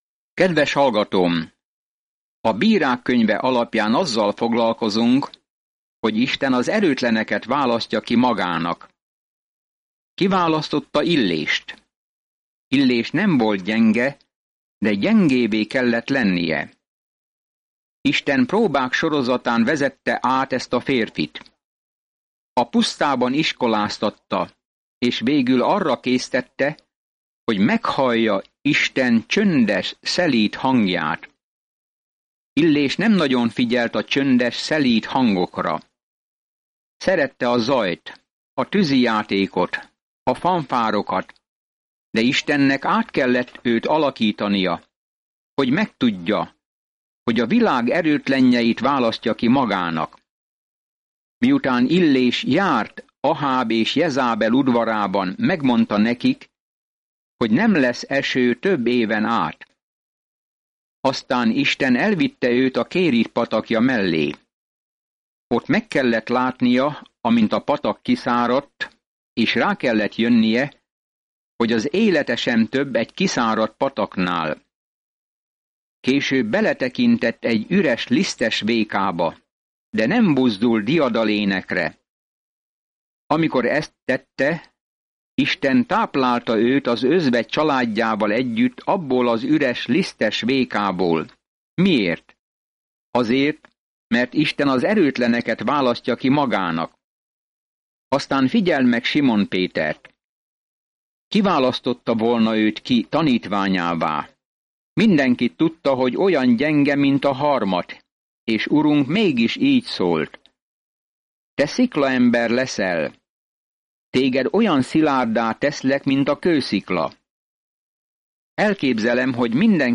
Napi utazás a Bírákon keresztül, miközben hallgatod a hangos tanulmányt, és olvasol válogatott verseket Isten szavából.